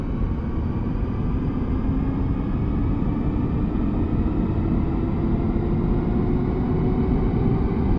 enginestart.ogg